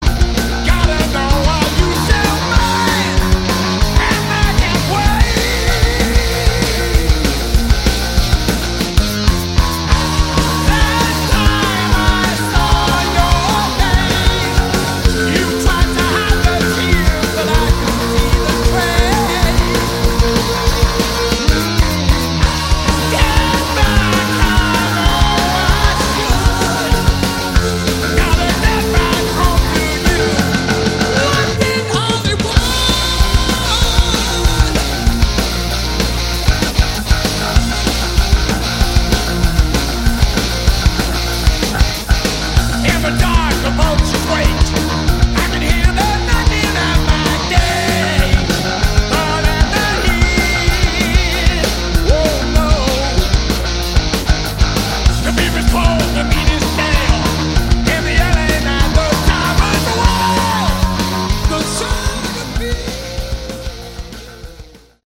Category: Hard Rock
vocals
bass
guitar
drums